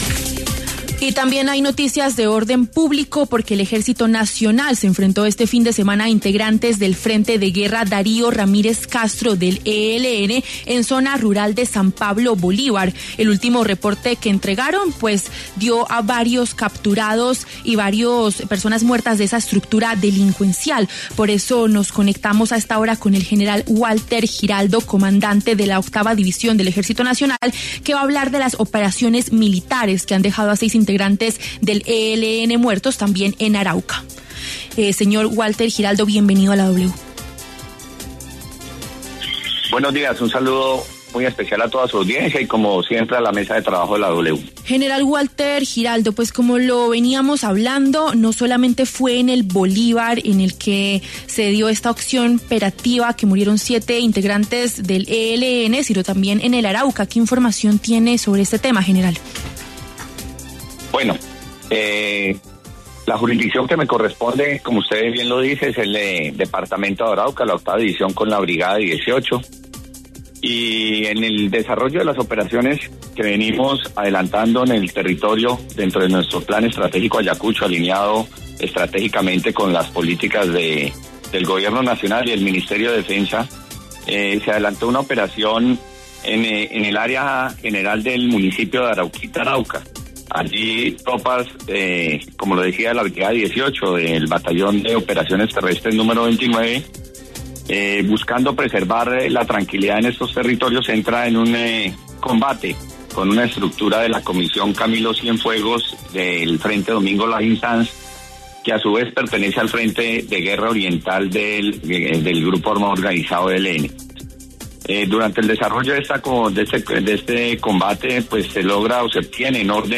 En diálogo con W Radio, el comandante de la Octava División del Ejército Nacional, el general Walther Giraldo, entregó detalles de la operación militar llevada a cabo en zona rural de Arauquita, Arauca, en donde murieron seis integrantes del ELN y se rescató a un menor de edad que había sido reclutado forzadamente por el grupo criminal.